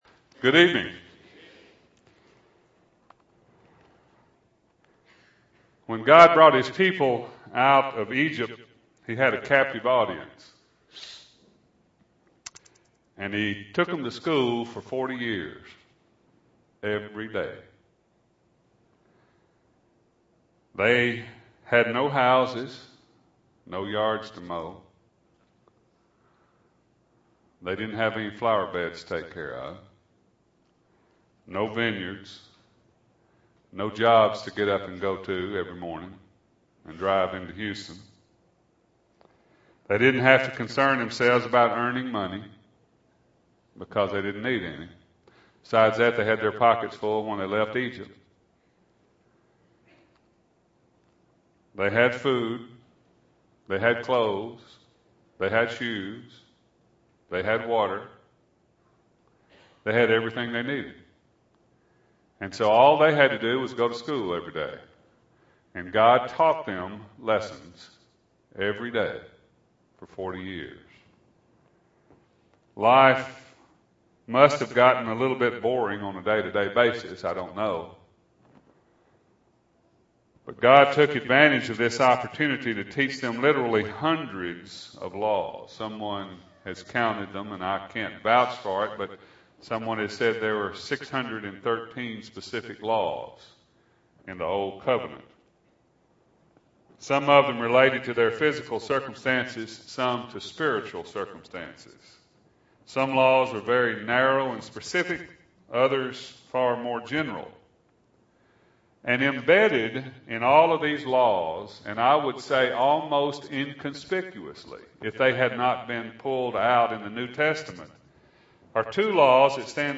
2008-04-27 – Sunday PM Sermon – Bible Lesson Recording